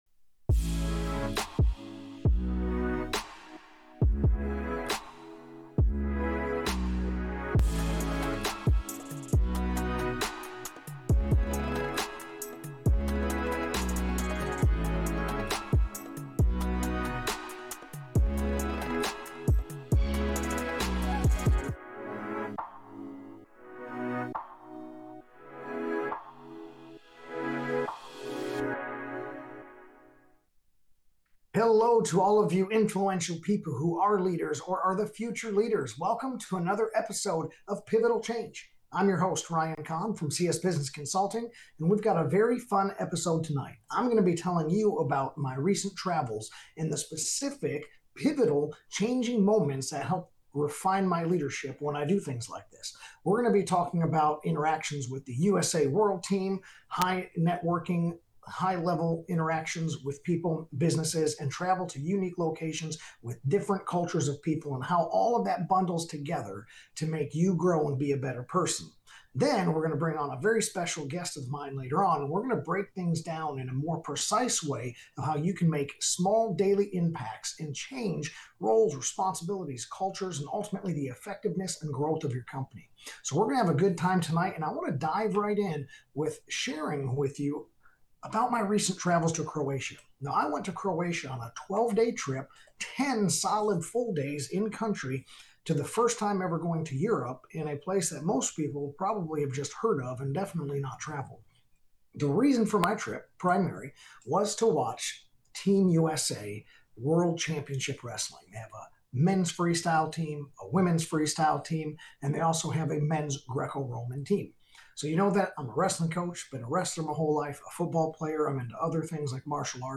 Later, a special guest joins to explore how small, intentional actions can shift company culture, inspire others, and create lasting impact.